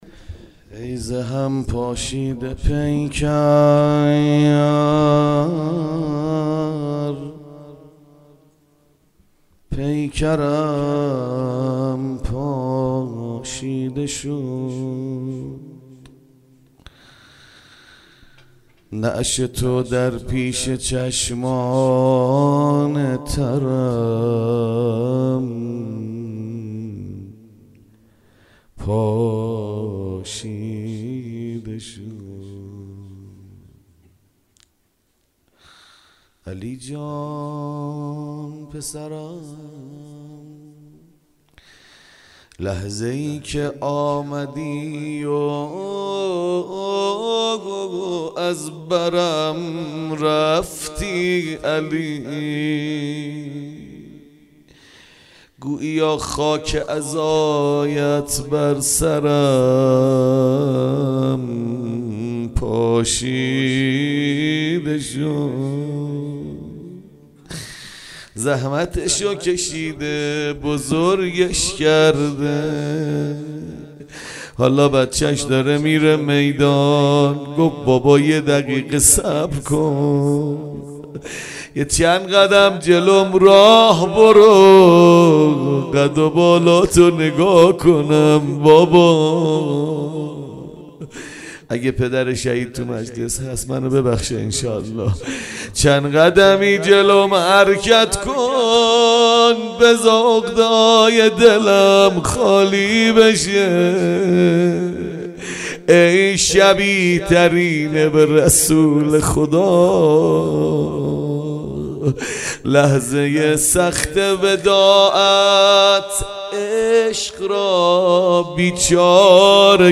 شب هشتم محرم 1438
هیئت امیرالمؤمنین لهرانی های طالقان